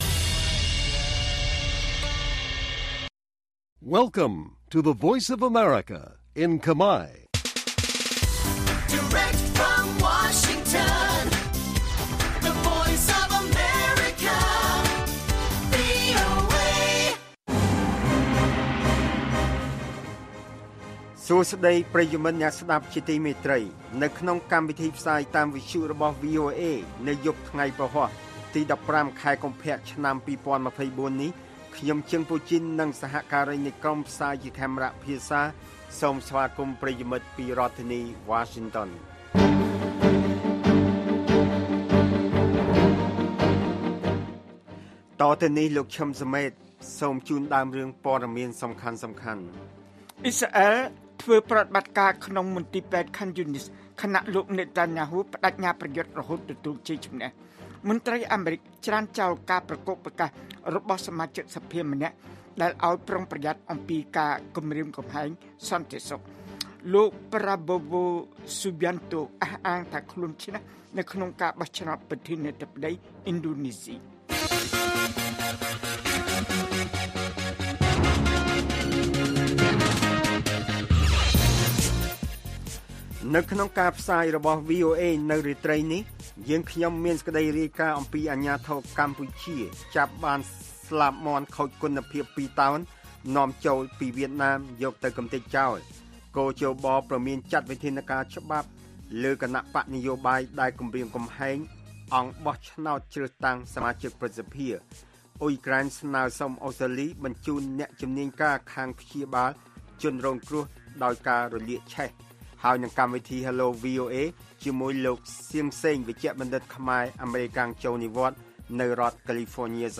ព័ត៌មានពេលយប់១៥ កុម្ភៈ៖ គ.ជ.ប ព្រមានចាត់វិធានការច្បាប់លើគណបក្សនយោបាយណាដែលគំរាមកំហែងអង្គបោះឆ្នោតព្រឹទ្ធសភា